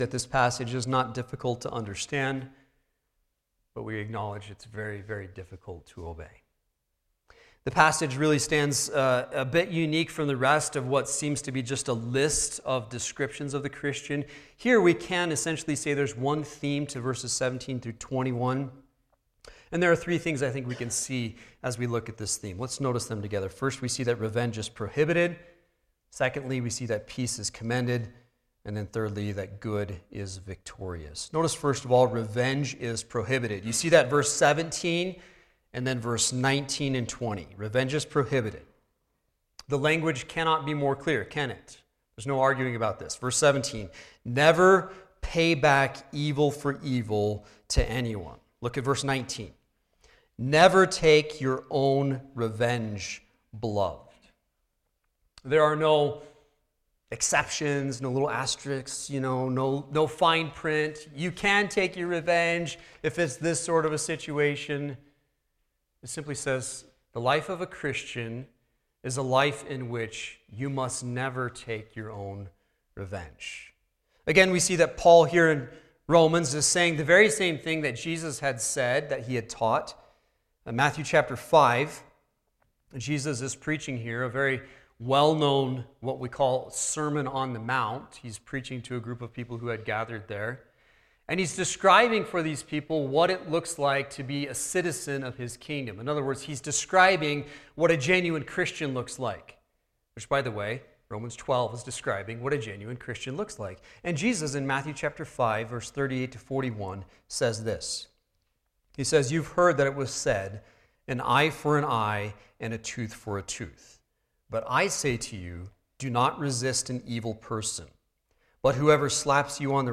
The recording starts approximately 10 minutes into the message.